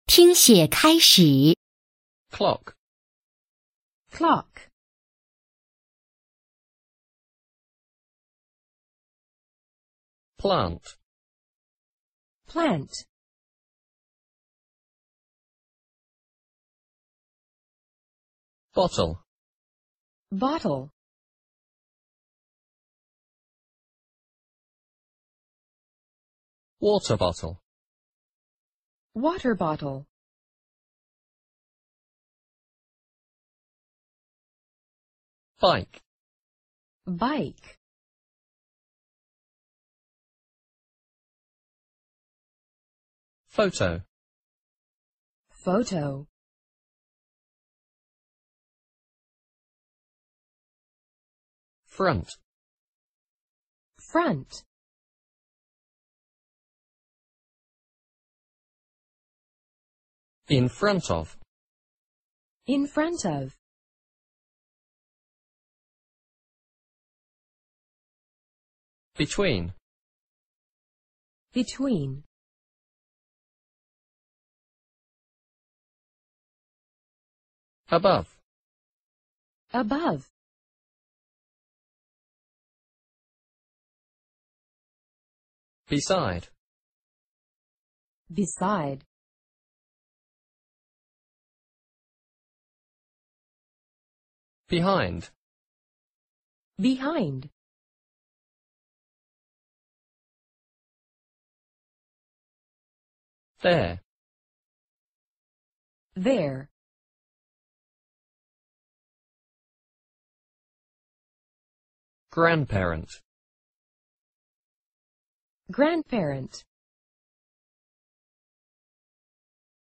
Unit 5 单词【听写】（人教版PEP五年级上册）